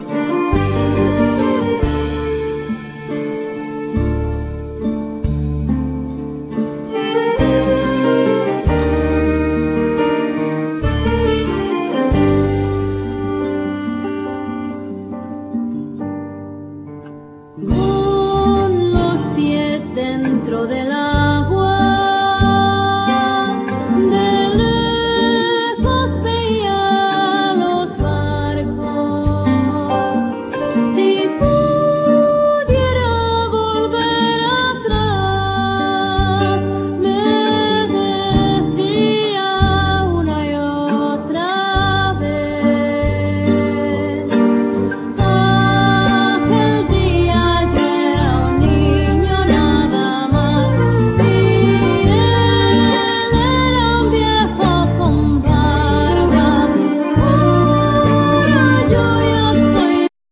Vocal
Flute,Travesera,Gaita
Violin,Mandolin
Ud,Buzuki,Zanfona,Percussions